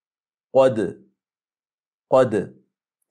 b ـــ Att blanda dess ljud med en antydan av ljudet av en dhammah, som i: